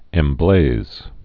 (ĕm-blāz)